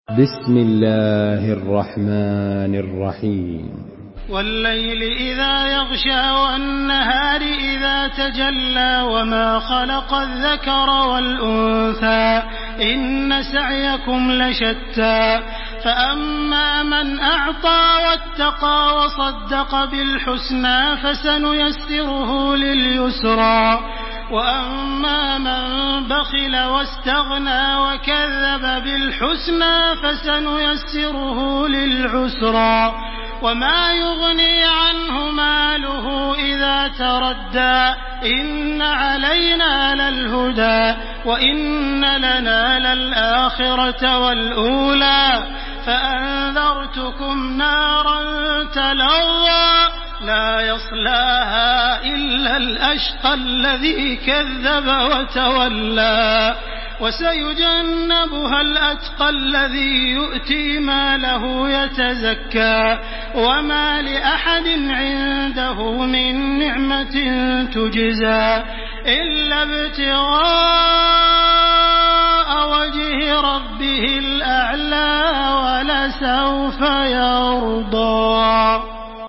تحميل سورة الليل بصوت تراويح الحرم المكي 1429
مرتل